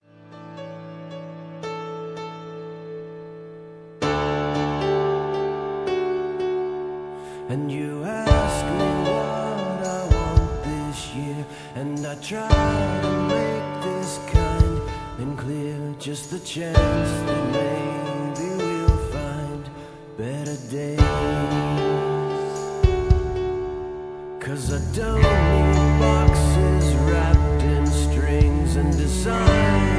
Tags: karaoke , backingtracks , soundtracks , rock